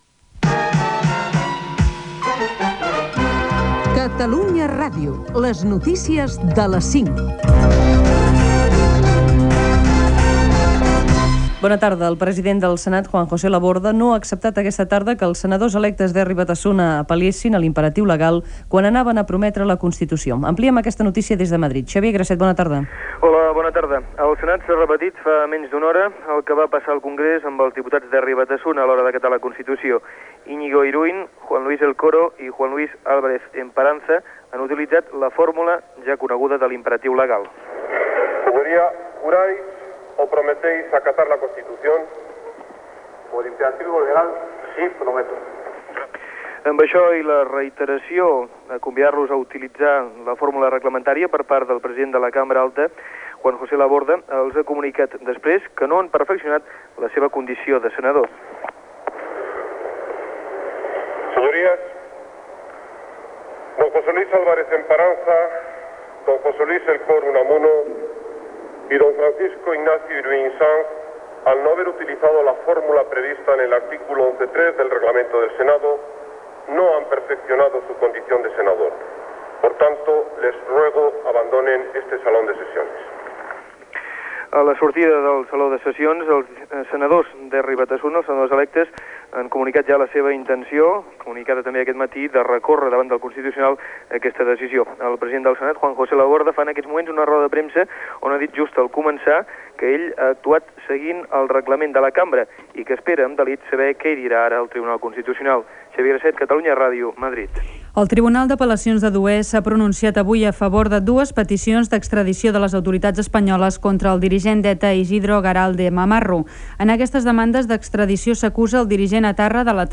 Careta del programa, jurament d'alguns senadors espanyols per imperatiu legal, extradició d'un integrant d'ETA, Iraq, accident del Talgo de RENFE, etc. Informació esportiva i careta de sortida
Informatiu